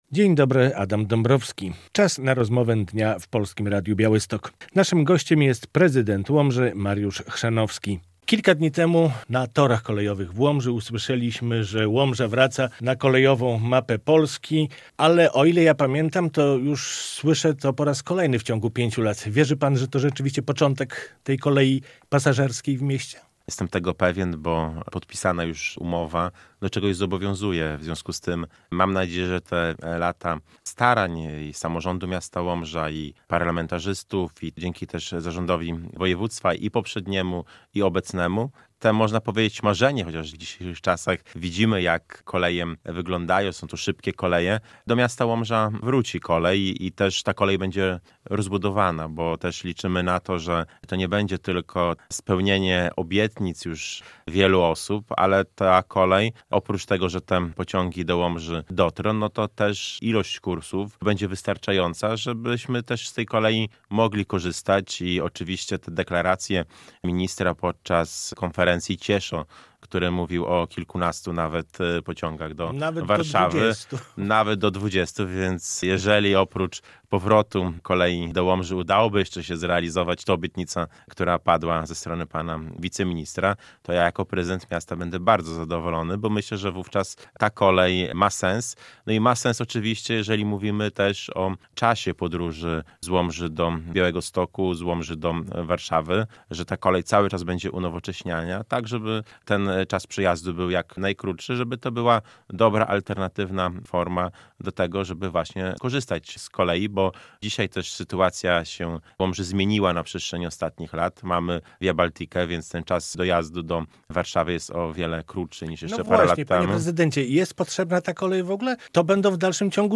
Radio Białystok | Gość | Mariusz Chrzanowski - prezydent Łomży
Pociągi pasażerskie są szansą dla Łomży i dla jej mieszkańców - stwierdził gość Polskiego Radia Białystok Mariusz Chrzanowski.